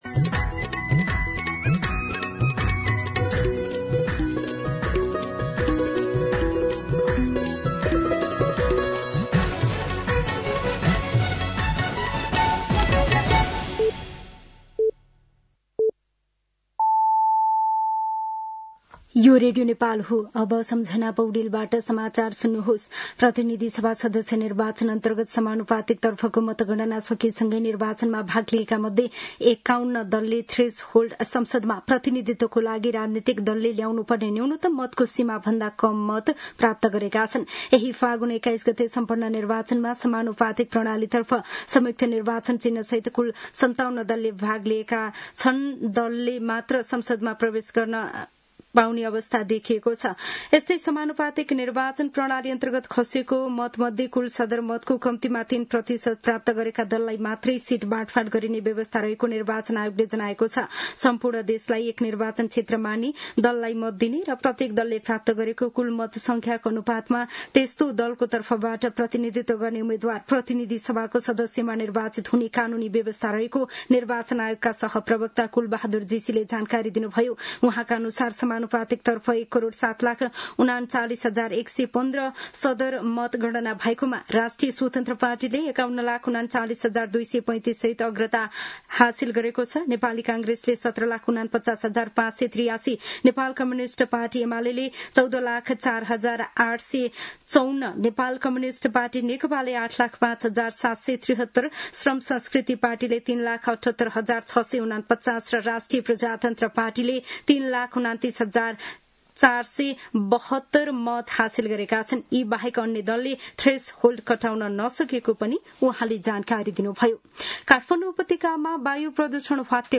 दिउँसो ४ बजेको नेपाली समाचार : २७ फागुन , २०८२
4-pm-News-27.mp3